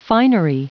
Prononciation du mot finery en anglais (fichier audio)
Prononciation du mot : finery